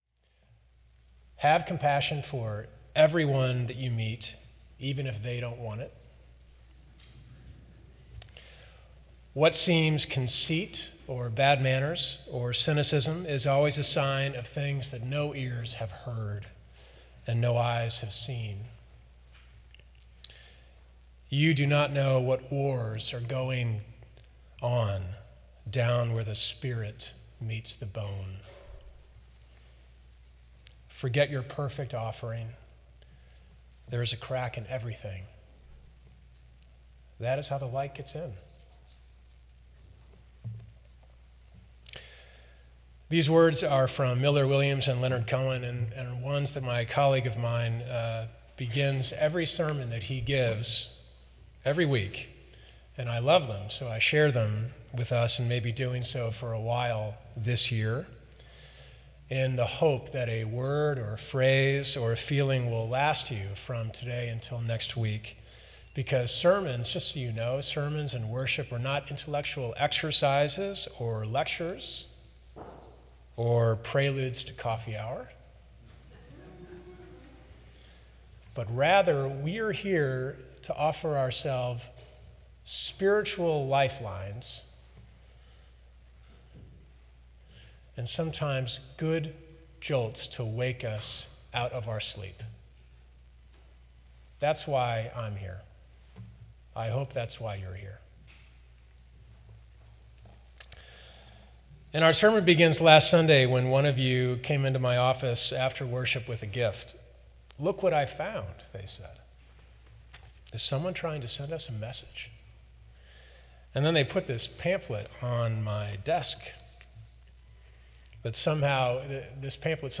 This is a recording of the complete worship service.
From Series: "2014 Sermons"